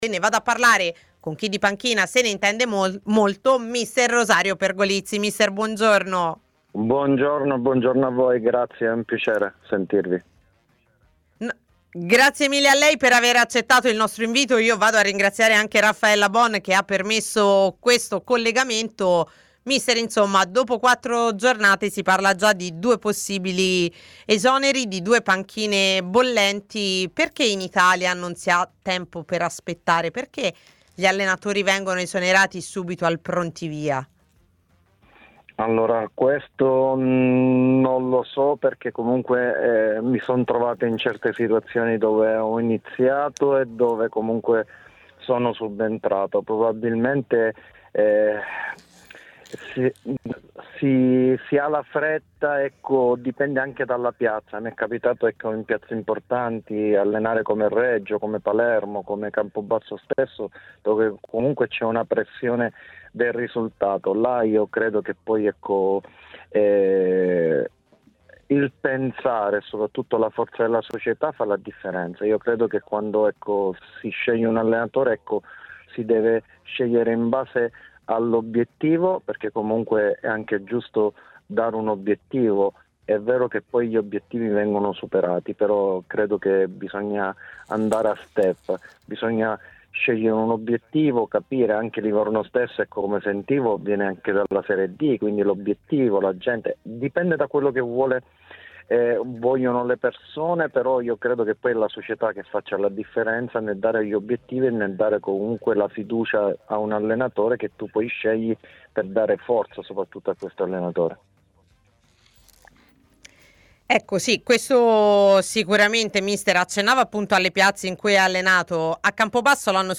È stato questo il primo tema toccato nell'appuntamento mattutino di A Tutta C, il format di TMW Radio interamente dedicato al mondo della Serie C